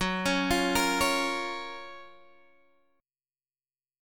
F#M7 Chord